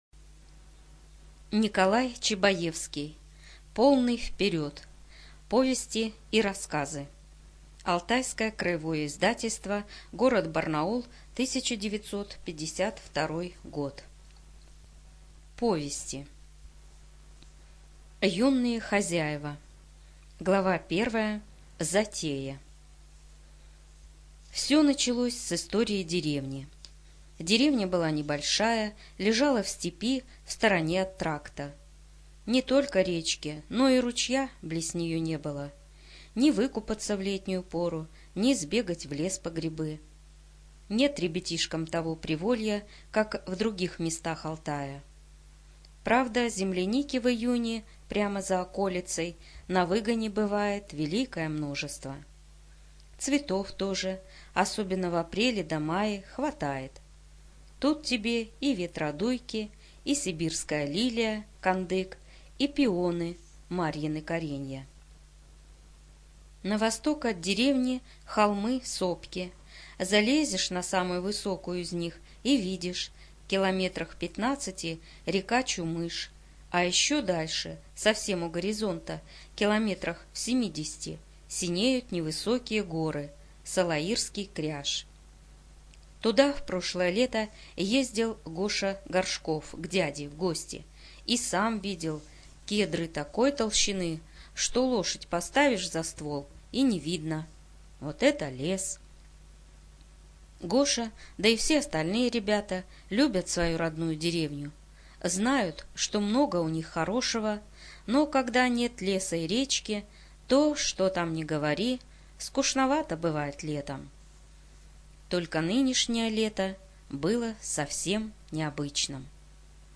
Студия звукозаписиАлтайская краевая библиотека для незрячих и слабовидящих